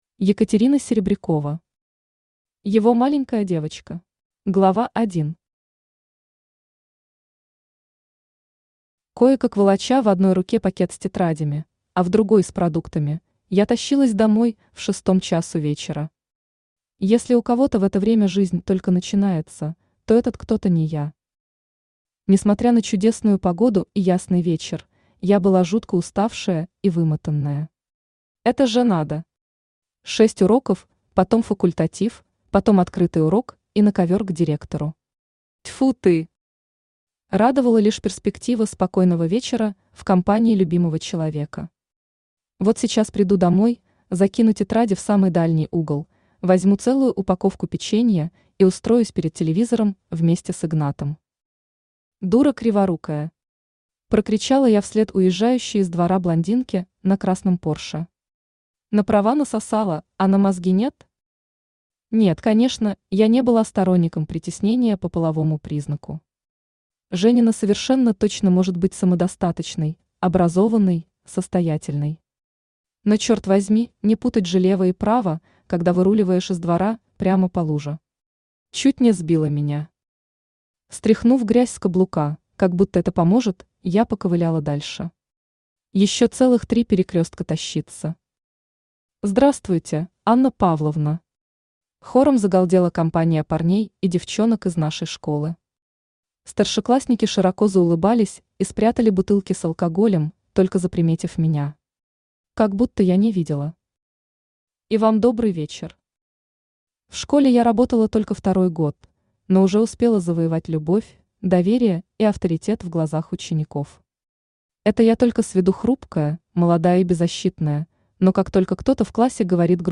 Aудиокнига Его маленькая девочка Автор Екатерина Серебрякова Читает аудиокнигу Авточтец ЛитРес.